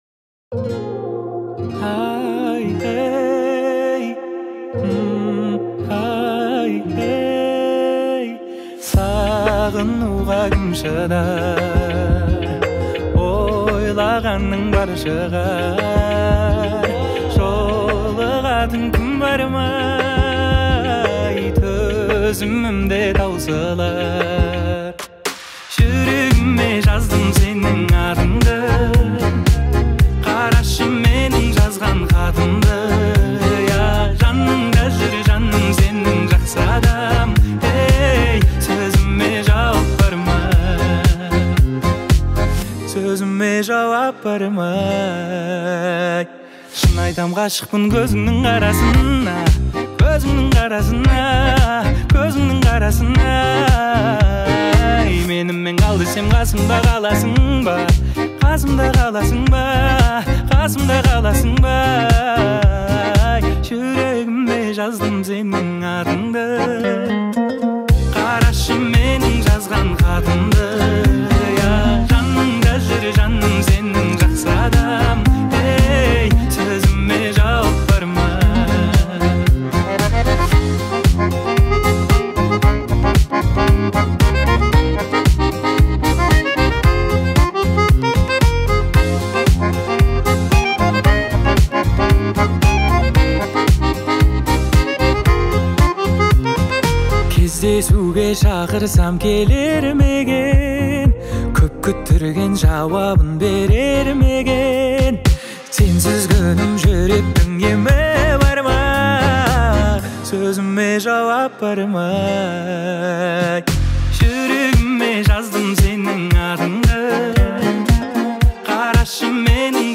Жанр: Казахские песни